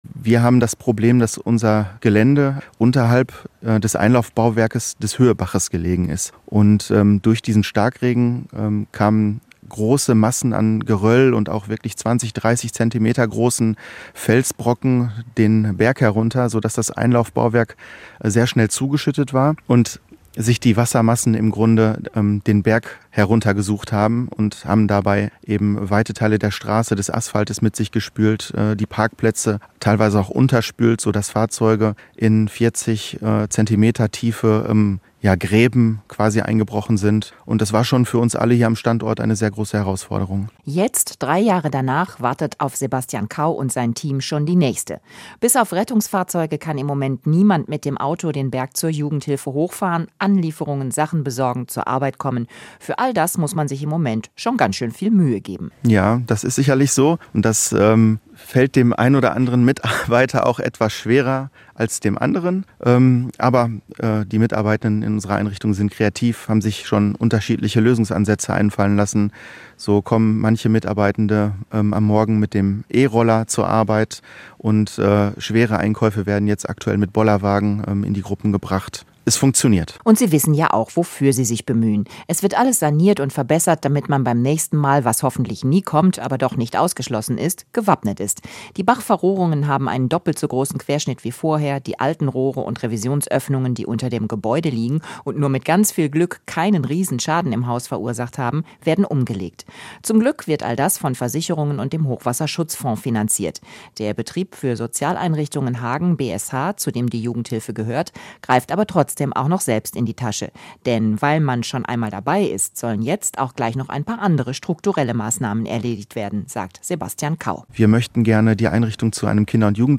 beitrag-hochwasserschaeden-jugendhilfe-selbecke.mp3